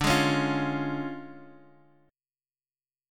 DmM7b5 chord